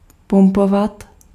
Ääntäminen
IPA : /pʌmp/